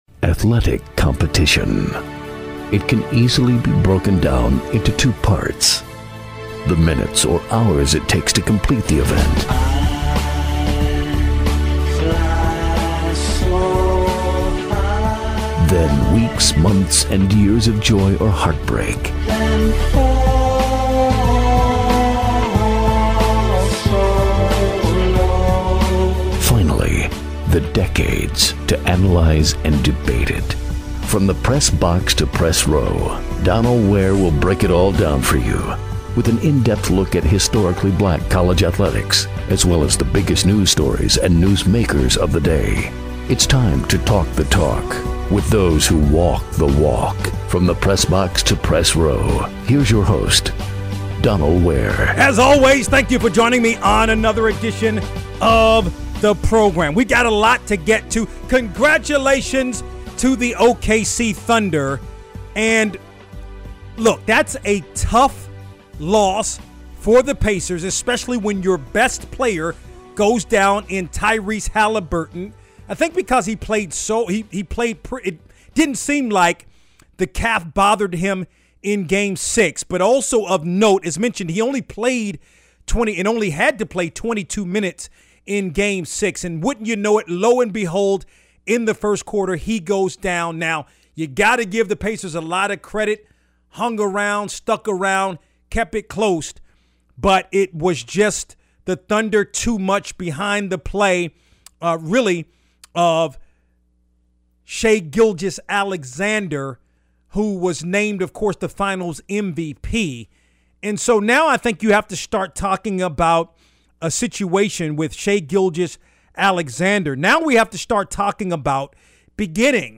Candid Conversation with Da’Vinchi.